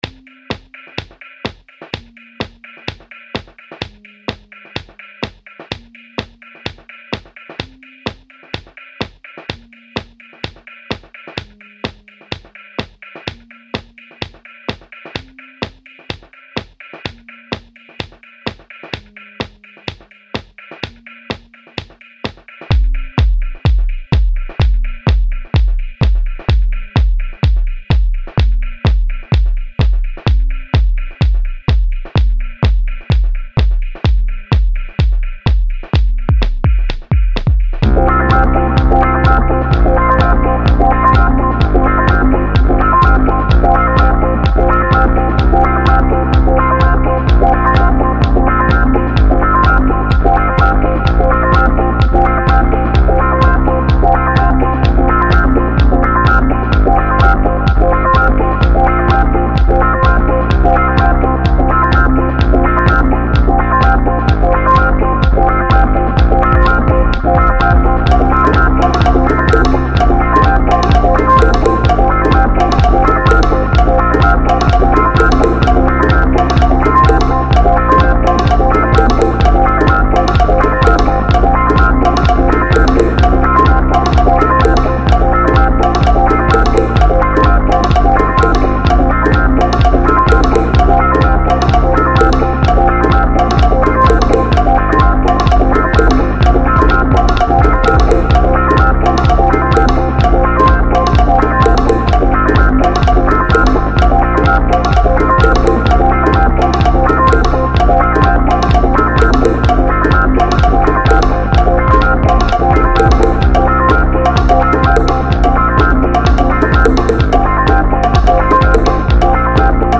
house
尻切れトンボでスマン（−−；